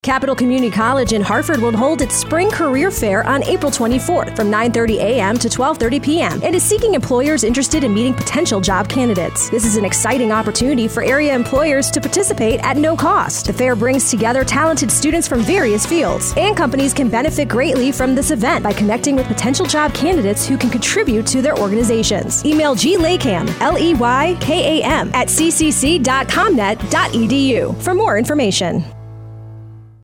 Radio Announcement